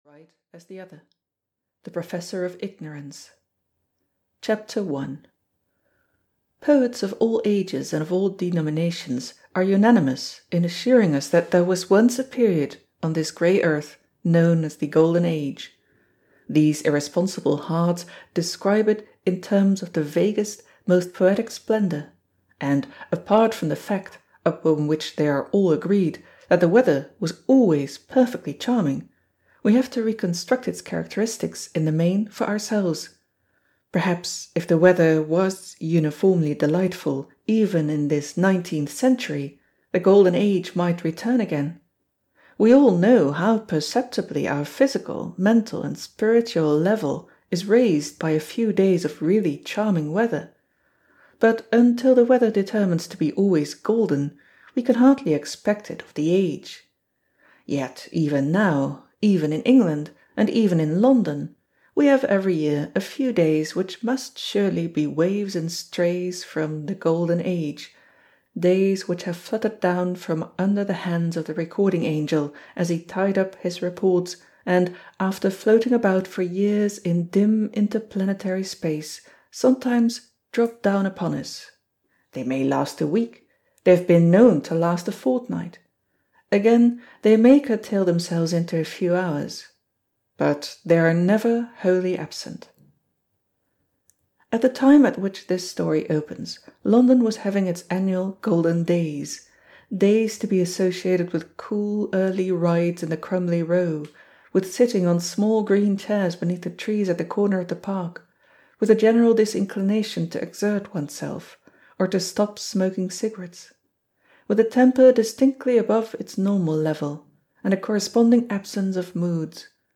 Dodo: A Detail of the Day (EN) audiokniha
Ukázka z knihy